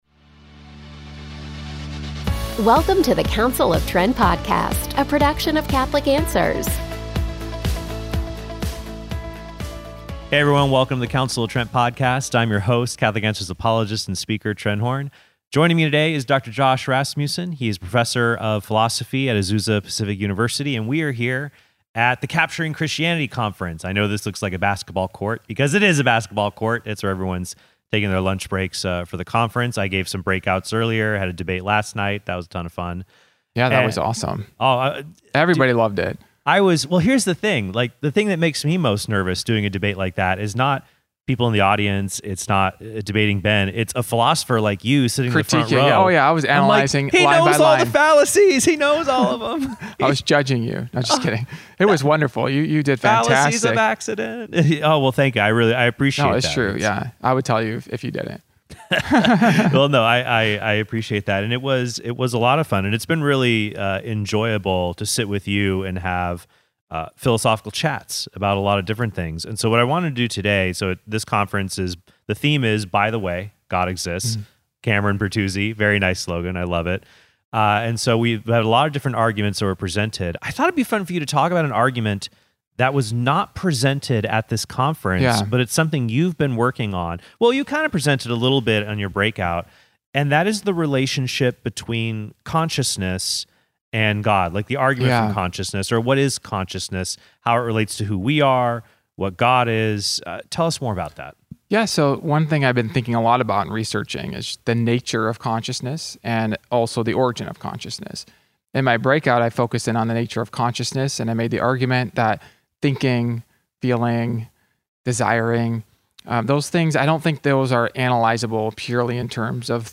And we are here at the Capturing Christianity Conference. I know this looks like a basketball court, because it is a basketball court.